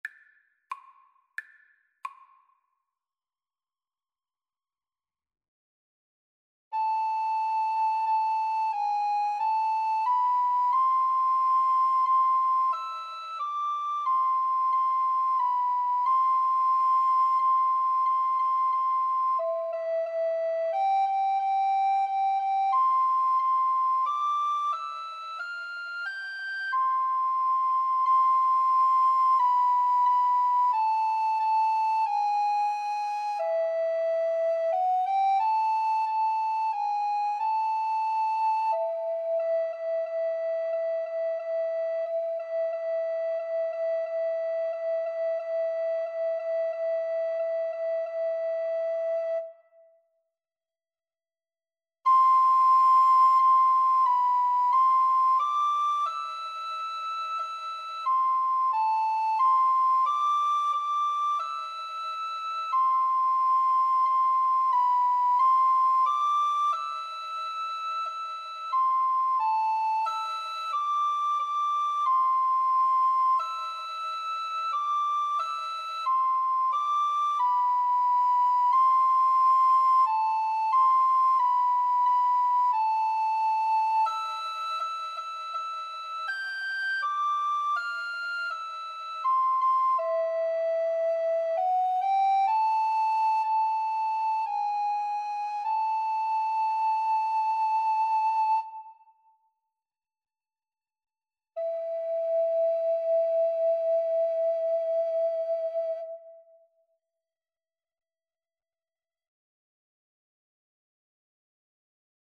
=90 Allegretto, ma un poco lento
Classical (View more Classical Alto Recorder Duet Music)